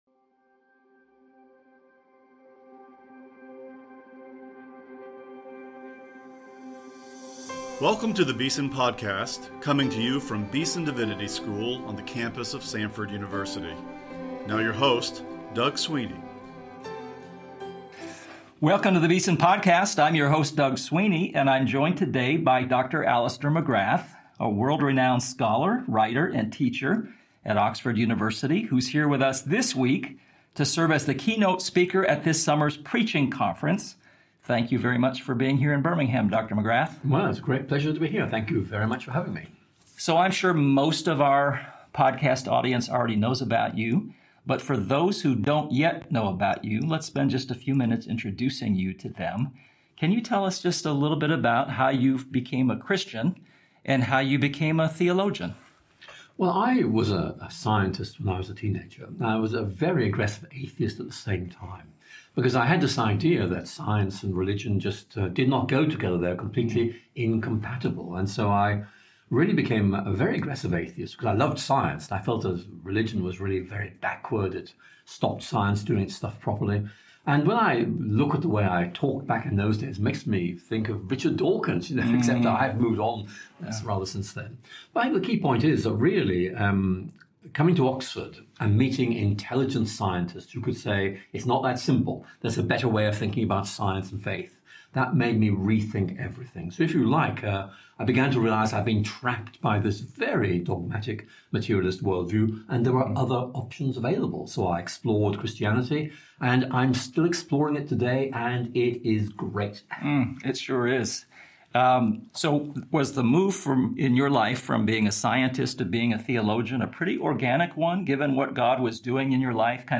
A Conversation with Alister McGrath